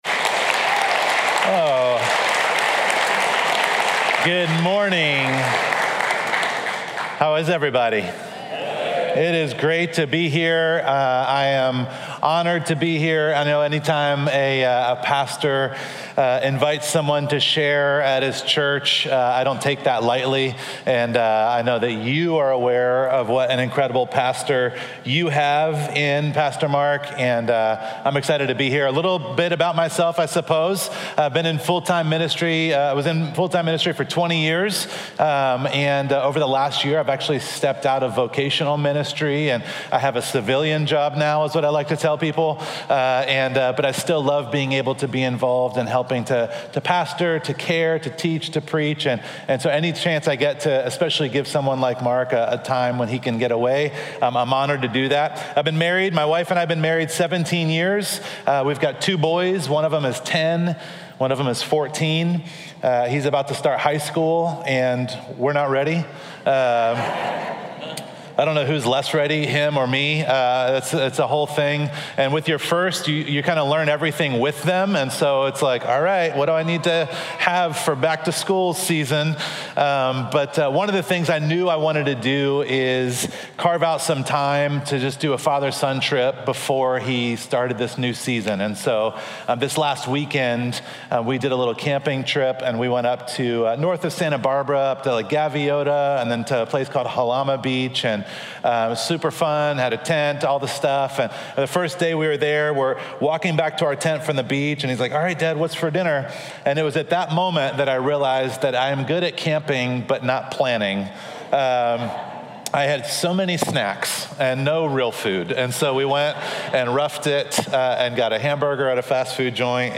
Listen to Message
Guest Speaker